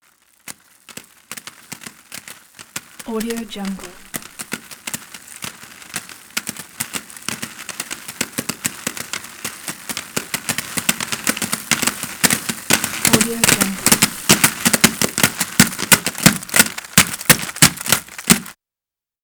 دانلود افکت صوتی اسکیت چرخ دار یا اسکیت رولرز و تلاش برای سرعت بیشتر